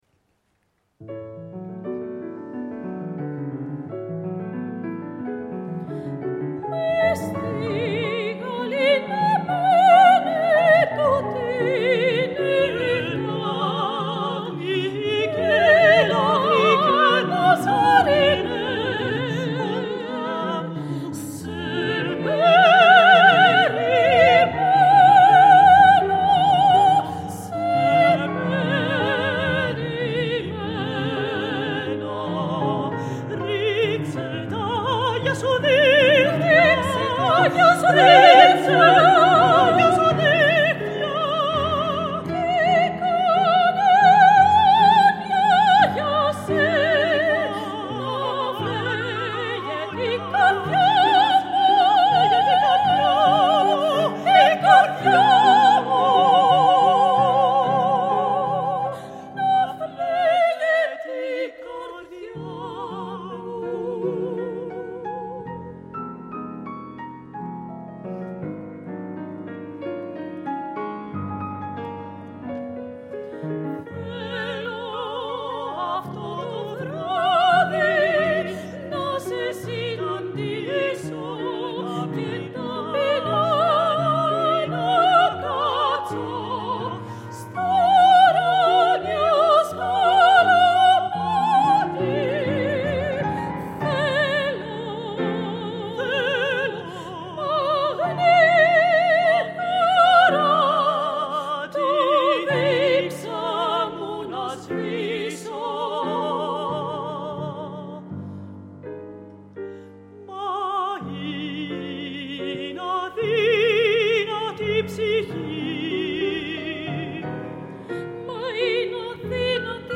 μέτσο-σοπράνο